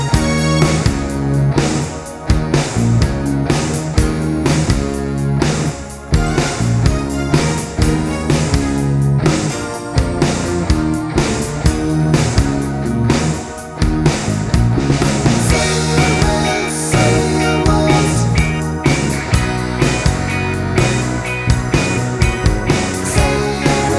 no Backing Vocals Soft Rock 4:17 Buy £1.50